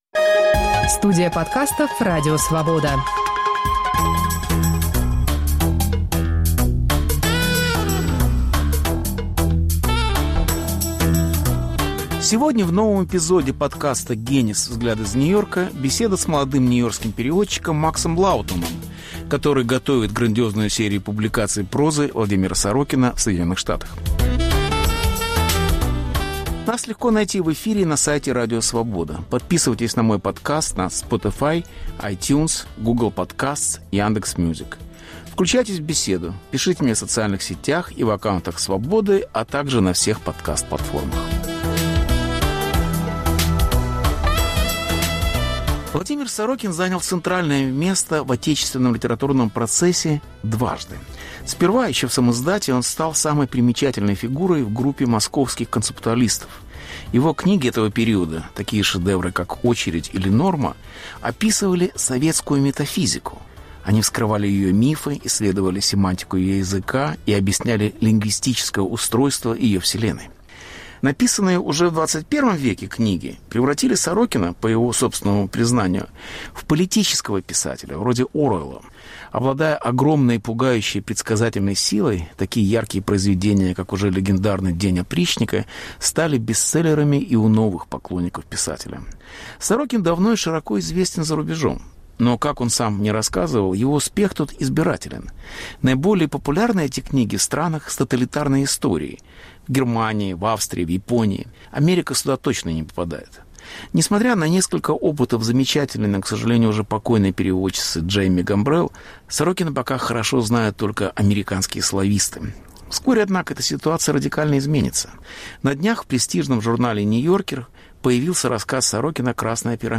Повтор эфира от 25 октября 2021 года.